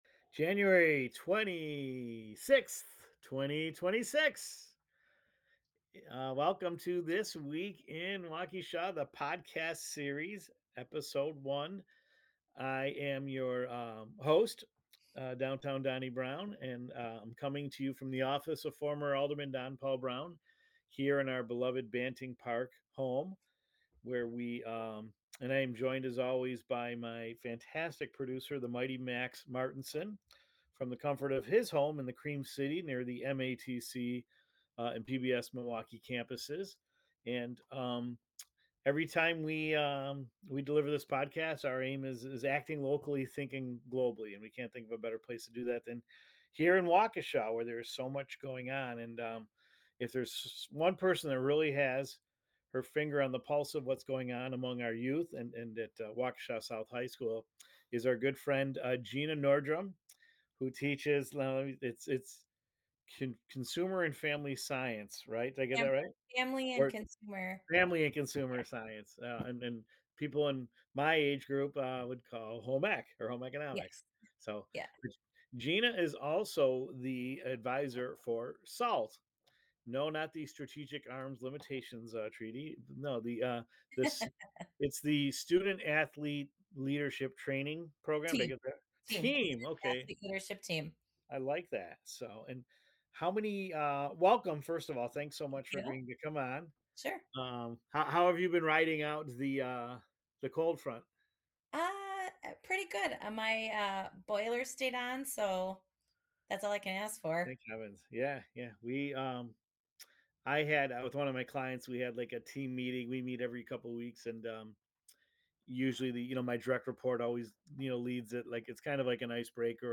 The conversation also touches on mentoring younger students, summer service projects, and why empowering young leaders—especially at the high school level—matters now more than ever.